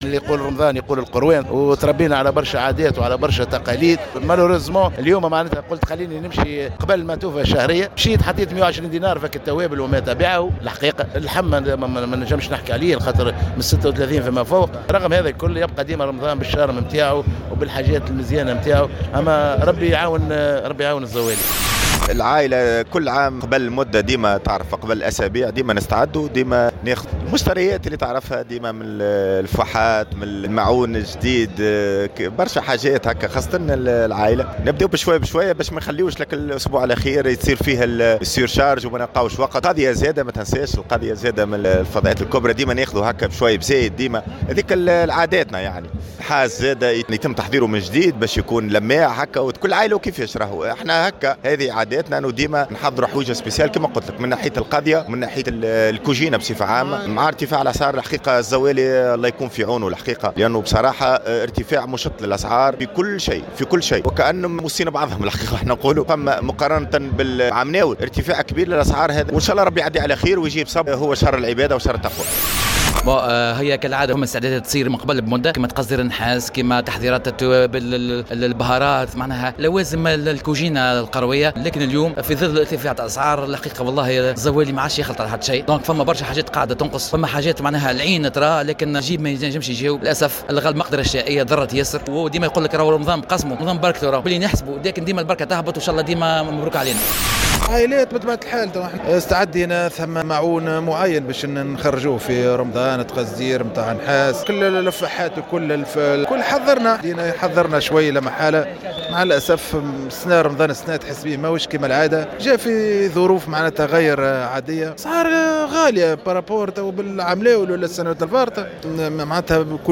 عبّر عدد من المواطنين عن تذمّرهم من ارتفاع الأسعار تزامنا مع حلول شهر رمضان الذي ترتفع فيه نسبة الاستهلاك.